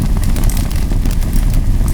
embers.wav